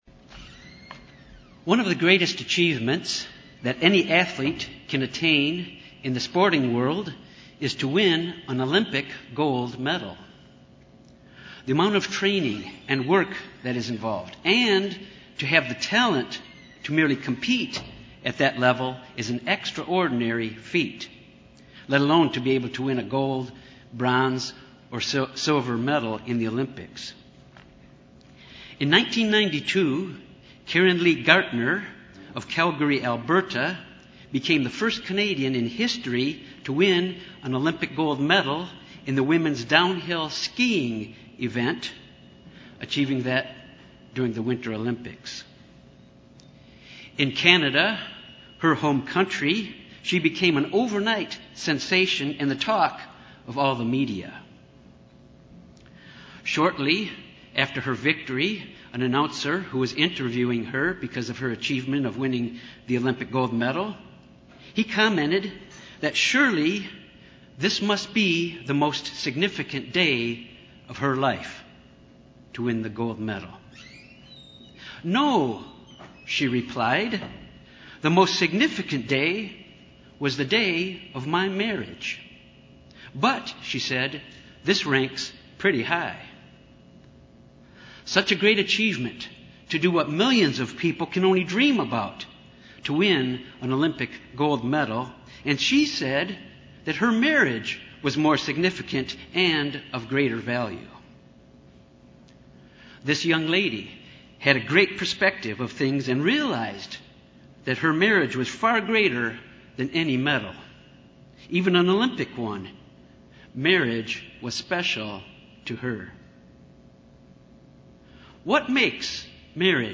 In this sermon, the principles, from the bible, to have a successful marriage are examined.
Given in Little Rock, AR